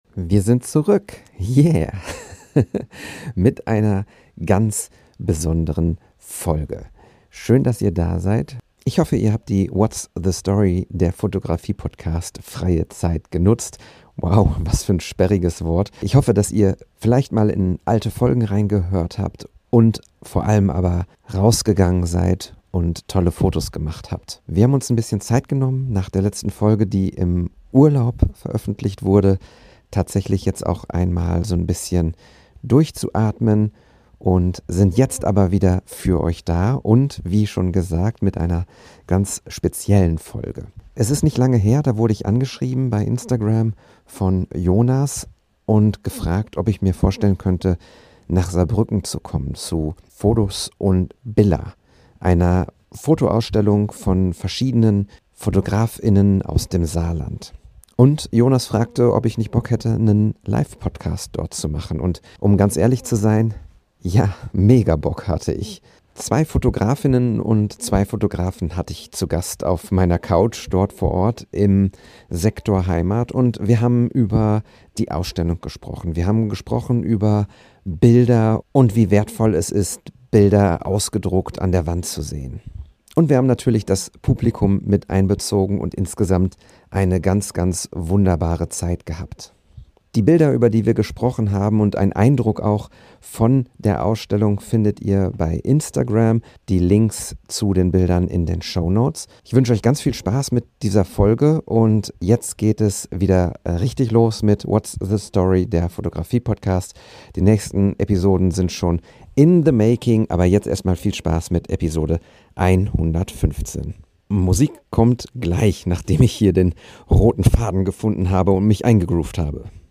EPISODE 115! Live-Podcast "Fodos und Billa" - Foto-Ausstellung in Saarbrücken
In einer Live-Folge, direkt aus Saarbrücken, taucht der Podcast in die Atmosphäre der Ausstellung „Fodos und Billa“ ein.